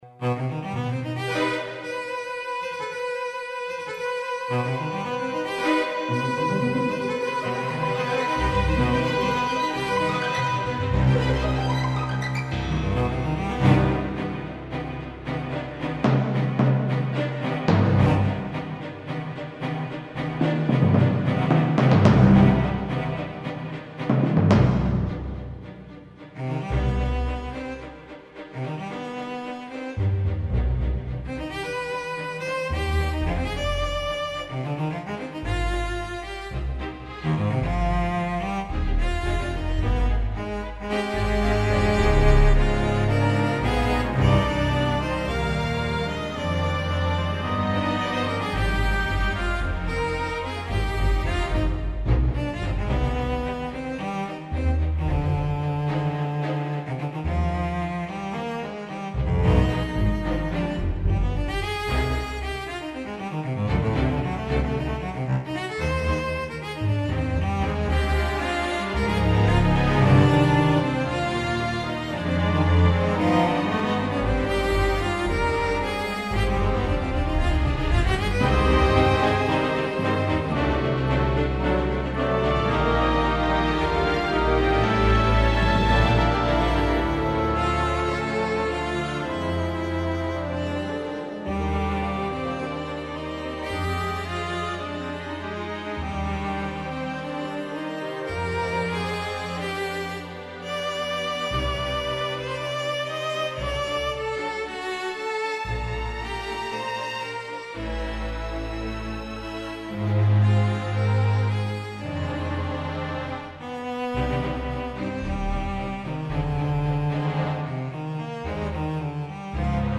This is a large scale 1 movement work, dramatic and  lyrical, but  essentially tonal and consciously conservative.
Scored for 2222, 4230,Timps(4),1 perc, piano and strings.
The piece has been recorded in a MIDI orchestral simulation, and a short section of the exposition can be heard
here- Cello Concerto -short taster clip (first subject)